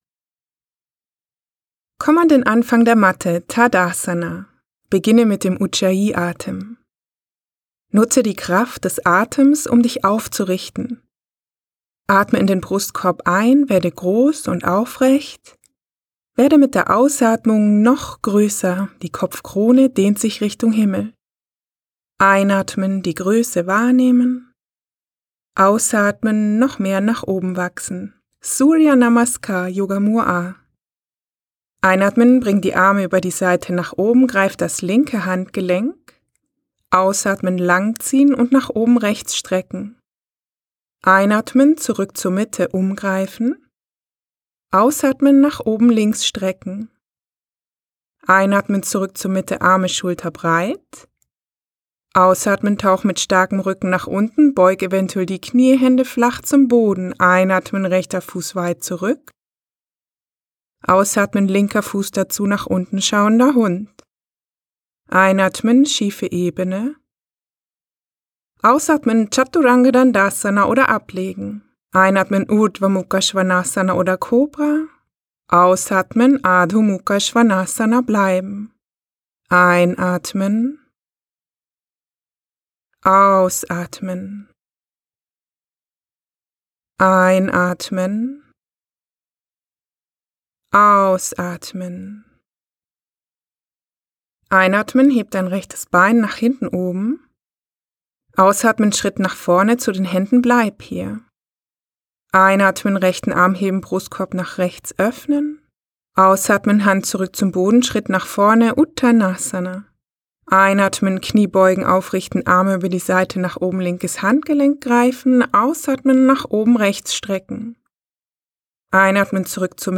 Eine Yogasession für jede Tageszeit